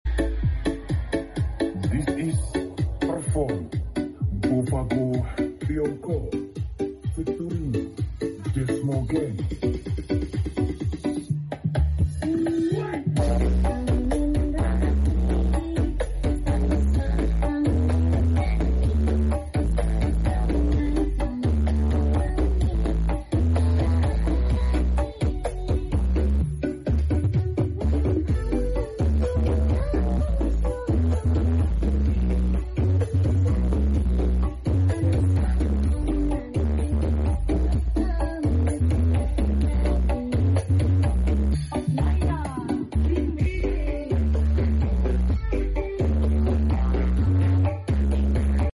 Desmo Music Cek Sound Karnaval Sound Effects Free Download